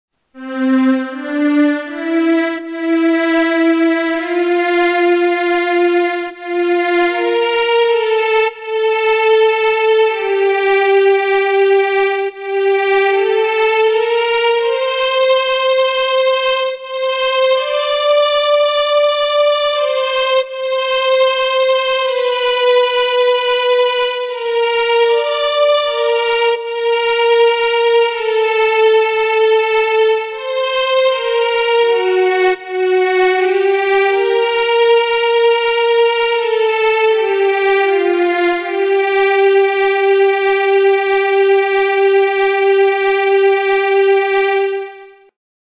Beyond the theme, played by violins, cellos repeat the theme with slightly modifying it.
The first three bars of the score contain the notes characteristic of the key of F major. Moreover, the first five notes characterize a perfect cadence.